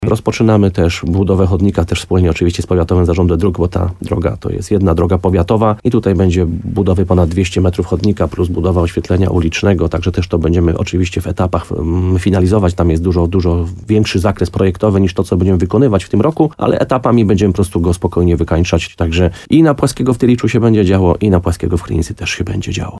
Jak mówi burmistrz uzdrowiska Piotr Ryba, wyłoniony jest już wykonawca, który wybuduje chodnik na tej samej ulice, tyle że od strony Tylicza.